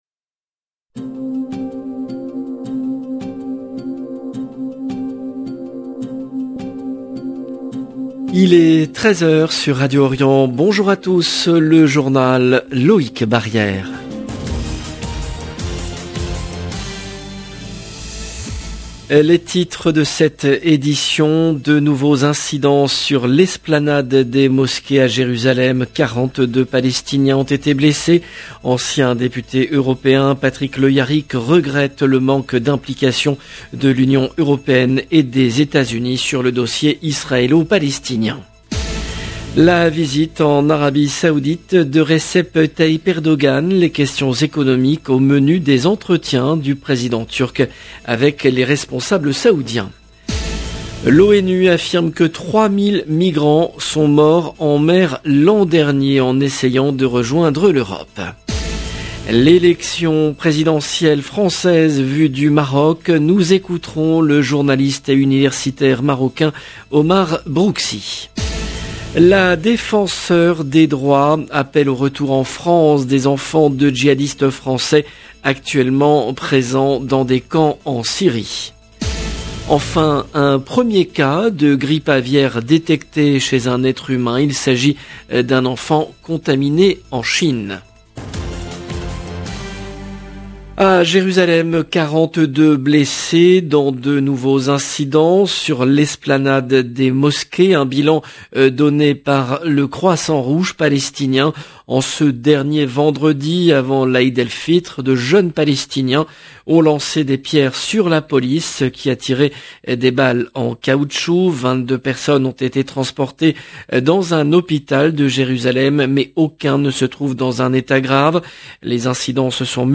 JOURNAL EN LANGUE FRANÇAISE